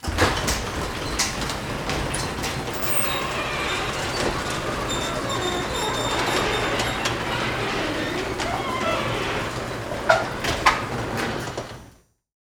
Car Garage Door Open Sound
transport
Car Garage Door Open